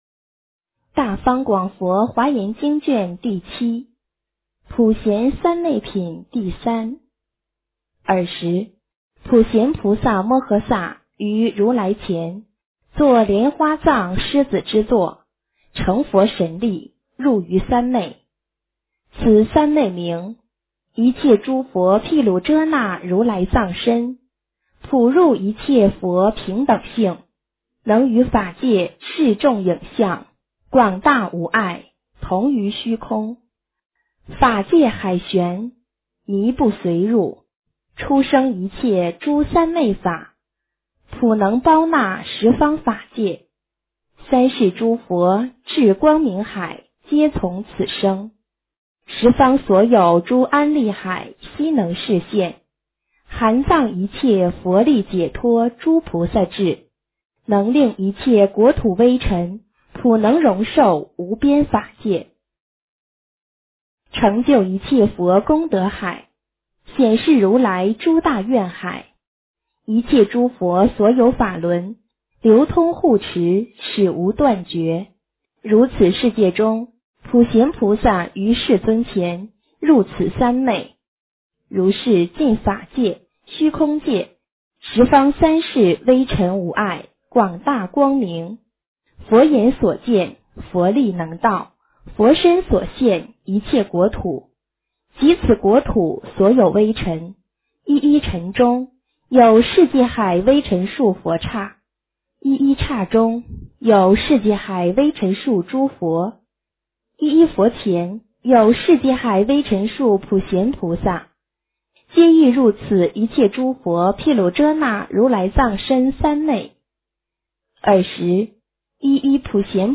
华严经07 - 诵经 - 云佛论坛